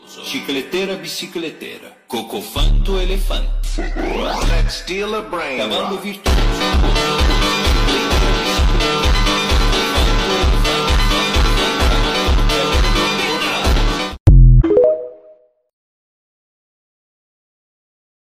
steal a brainrot phonk Meme Sound Effect